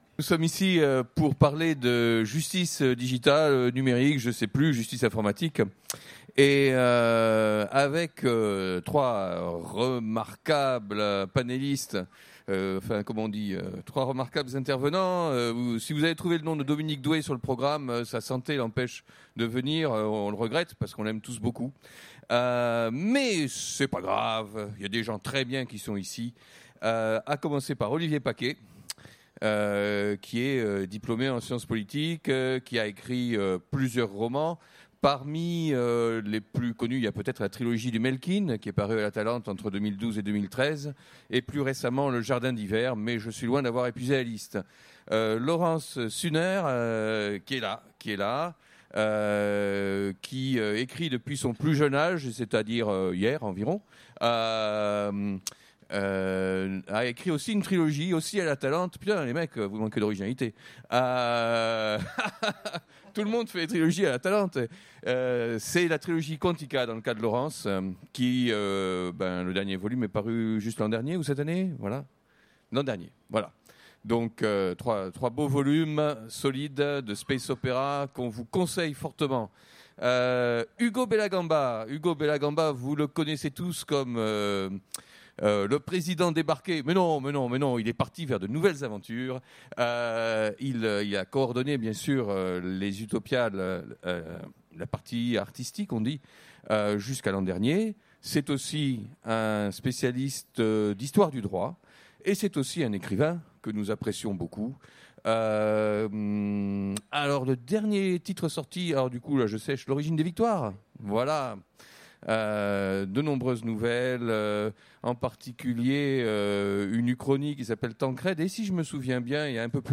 Utopiales 2016 : Conférence La justice numérique